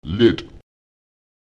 d [d] ist wie ein „ploppend“ artikuliertes d wie in Dach auszusprechen.
Lautsprecher led [lEd] klein